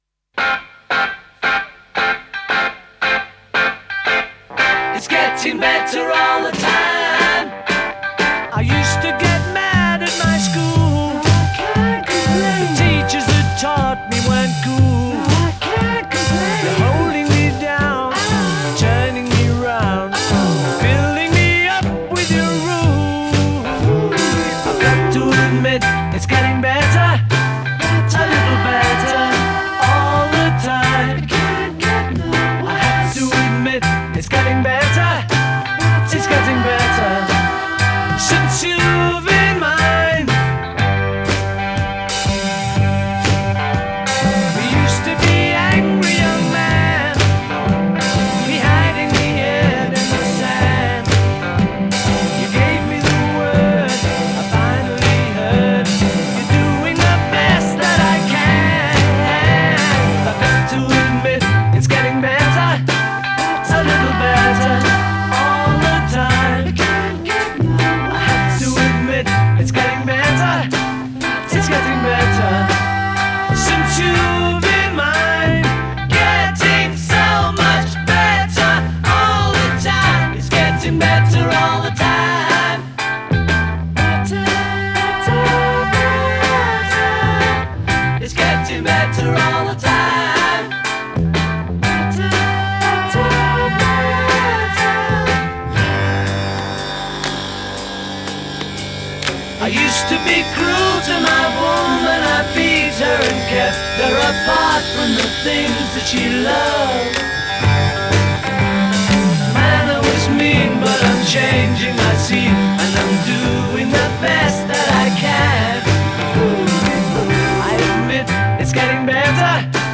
the ringing guitars